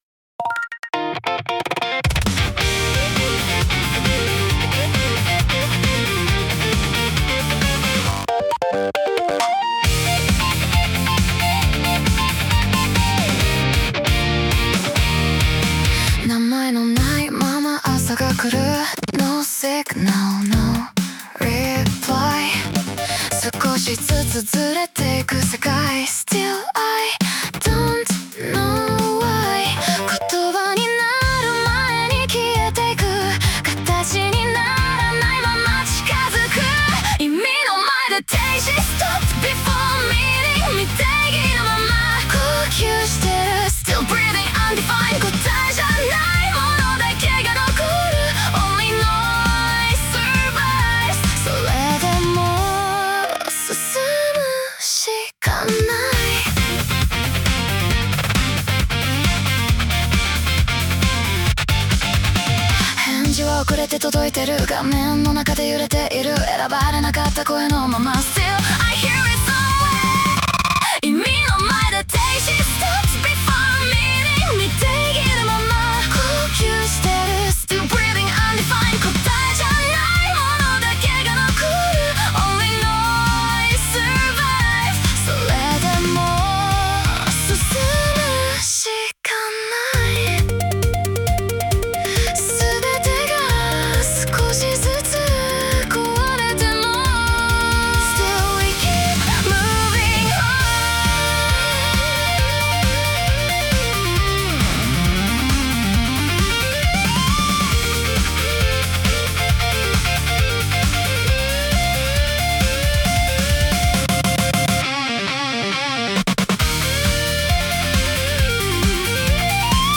女性ボーカル